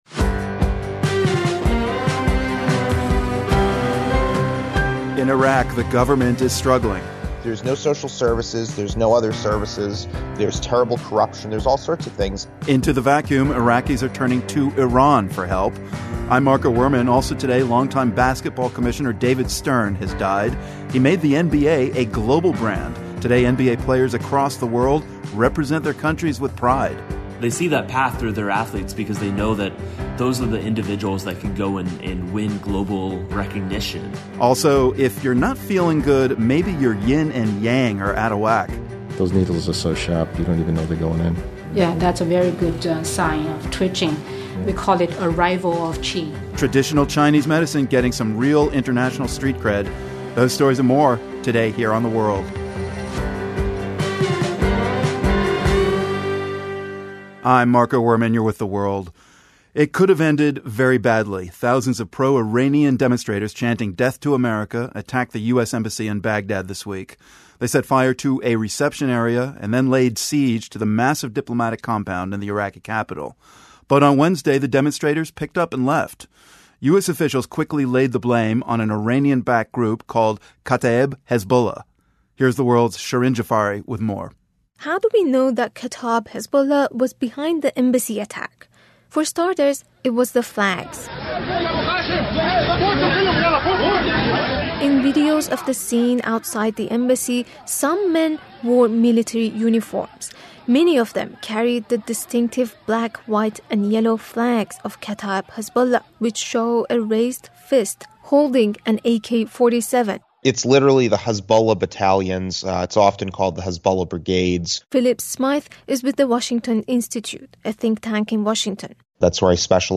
The World is a public media news program that relies on the support of listeners like you.